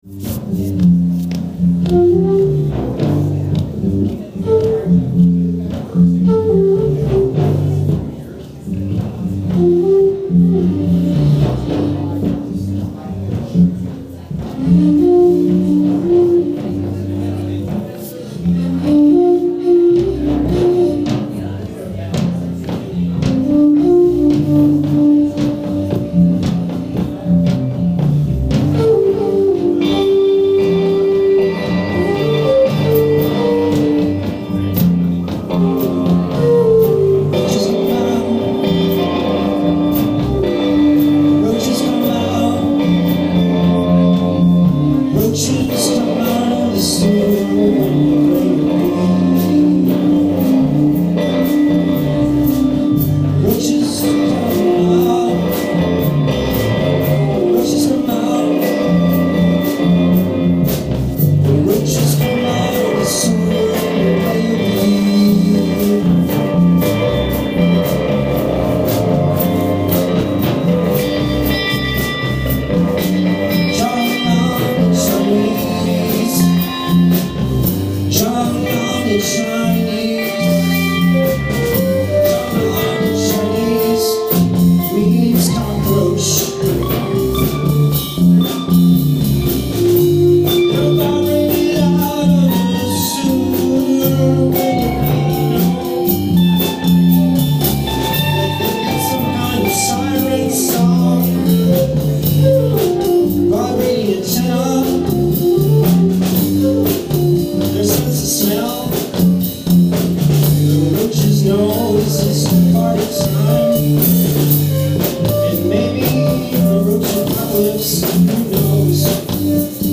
MOST MUSIC IS IMPROVISED ON SITE
Roach Rapture sitar
sax/flute